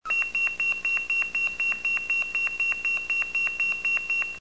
Les bips CCIR (5 tons), ce sont des BIPs dit "analogique"